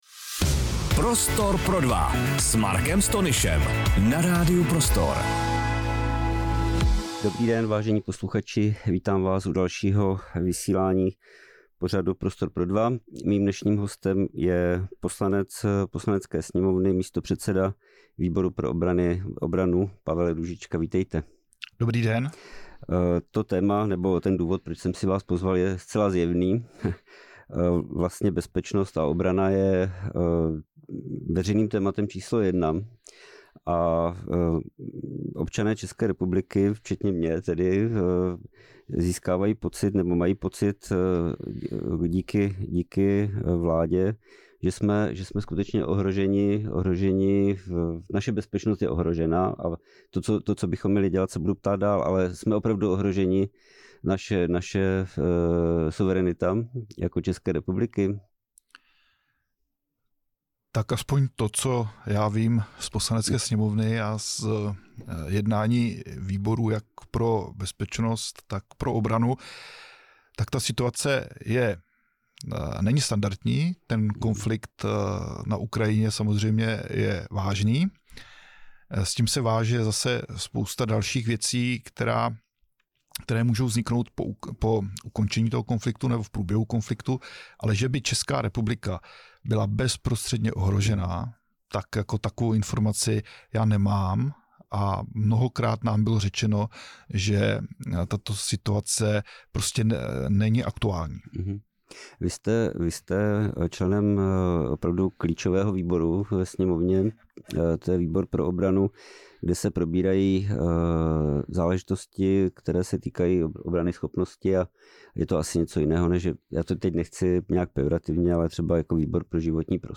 Bezpečnost a obranná strategie České republiky byly hlavním tématem aktuálního rozhovoru s místopředsedou sněmovního výboru pro obranu Pavlem Růžičkou.